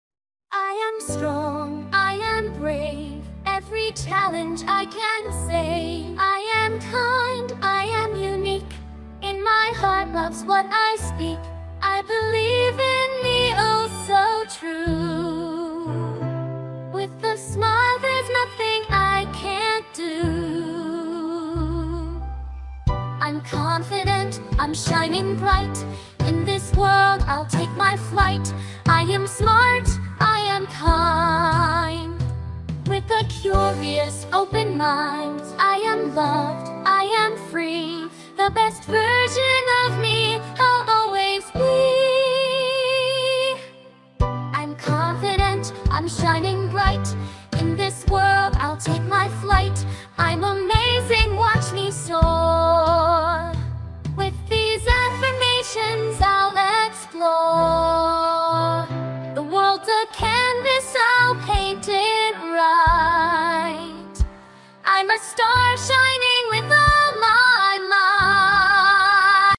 a song to inspire courage and strength in every child